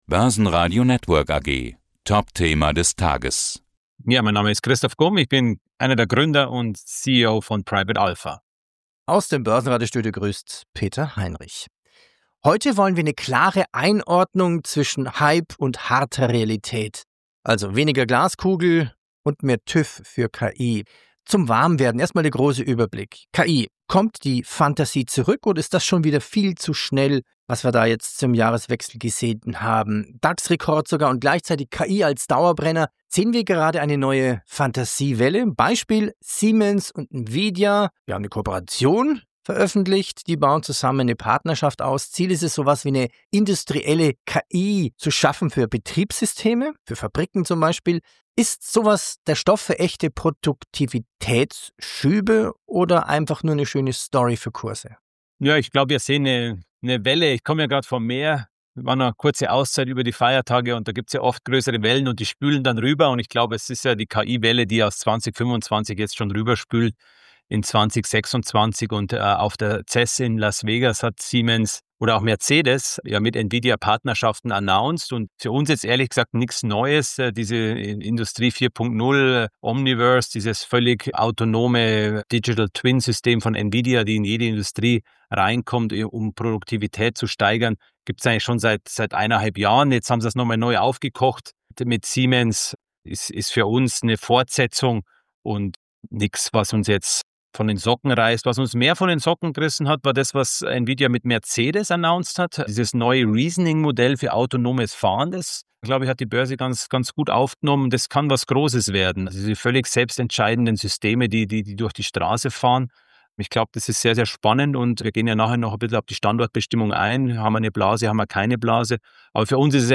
Hier geht’s zum Podcast Interview mit dem Börsenradio: